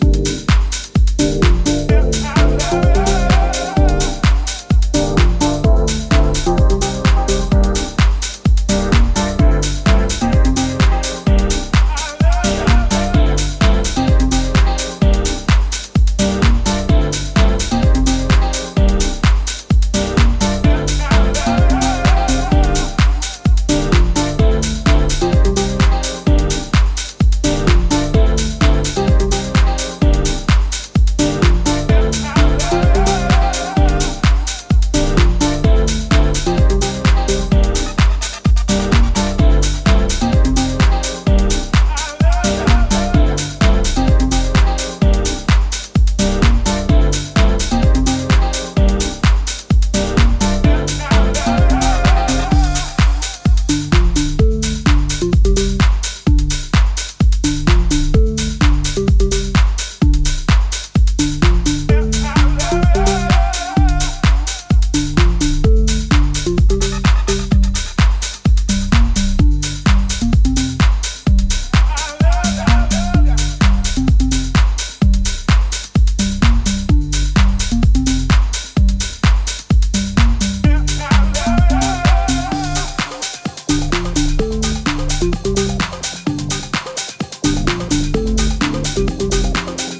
多彩なグルーヴやオーガニックでタッチでオーセンティックなディープ・ハウスの魅力を醸し、モダンなセンスで洗練された意欲作。